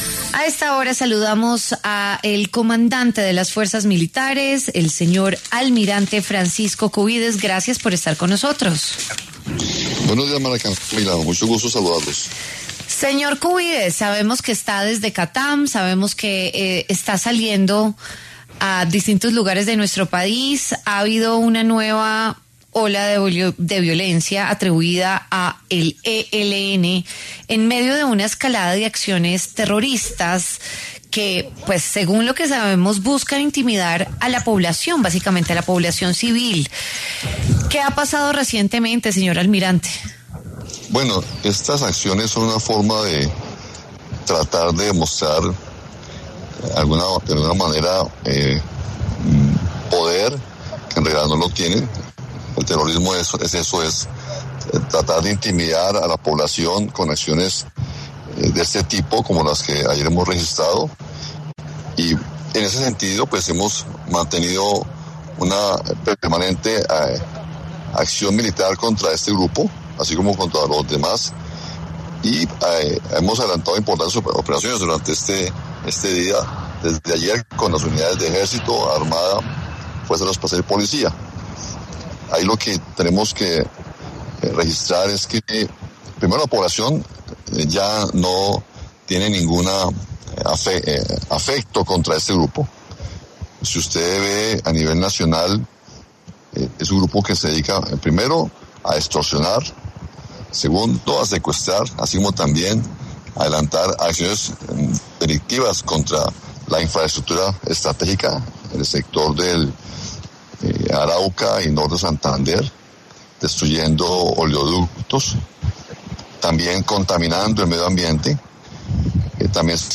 El almirante Francisco Cubides, comandante general de las Fuerzas Militares, habló en W Fin de Semana sobre las acciones criminales del ELN en el marco de su aniversario.